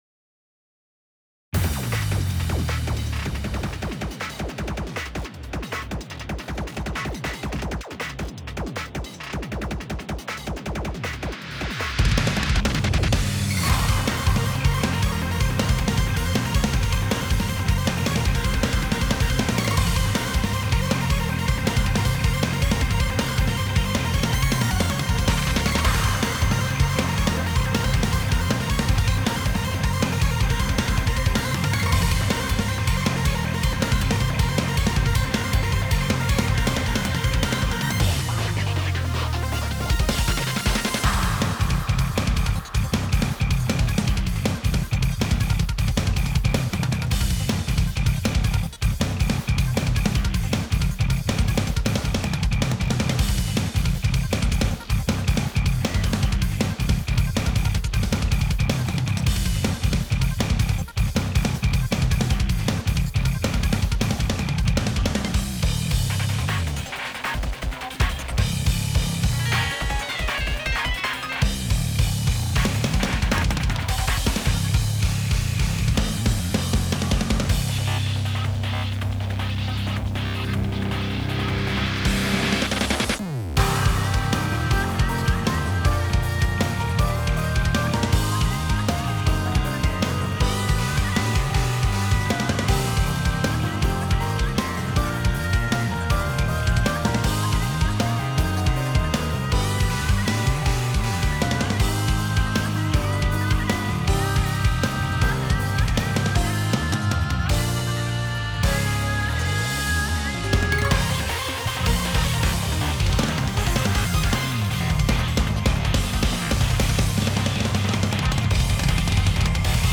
guitarless tracks